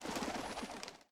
snd_birdspawn.ogg